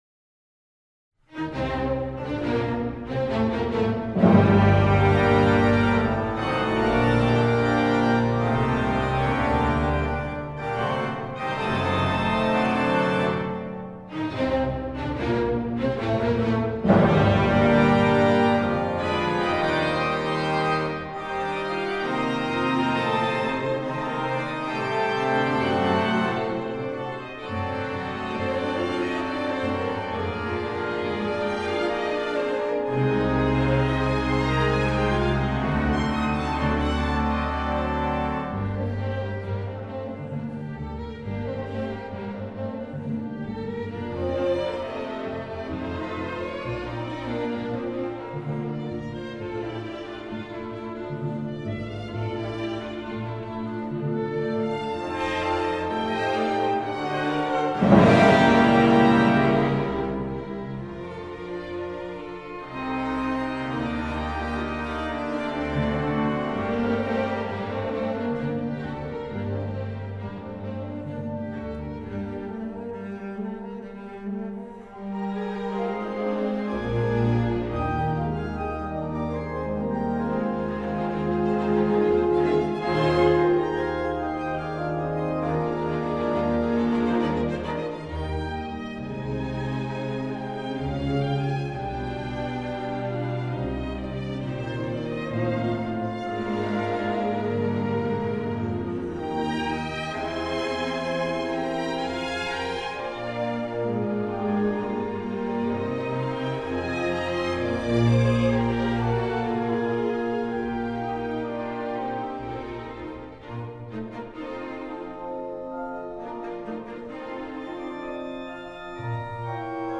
Ich biete sowohl professionelle Konzertmitschnitte als auch Musikproduktionen an. Dabei ist mein Bestreben in Räumlichkeiten möglichst guter Raumakustik aufzunehmen, um ein authentisches Klangbild zu erreichen.
Klangbeispiele (Orchester)